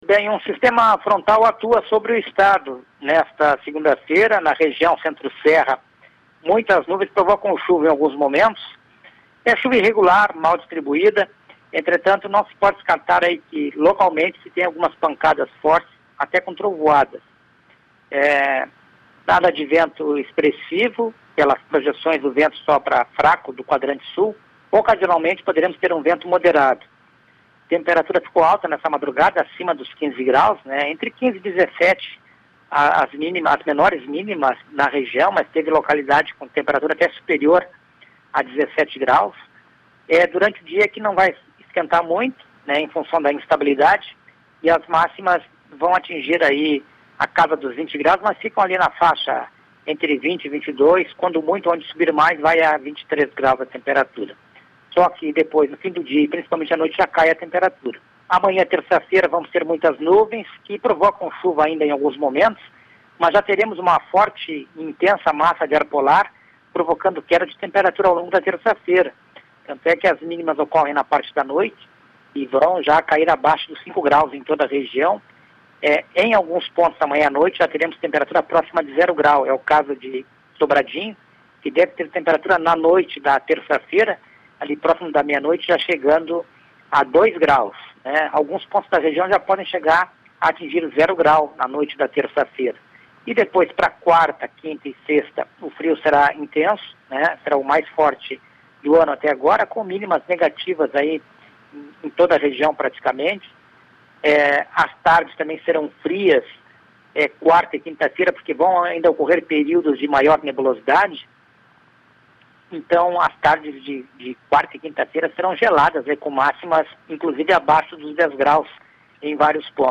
Confira a previsão do tempo para o Centro Serra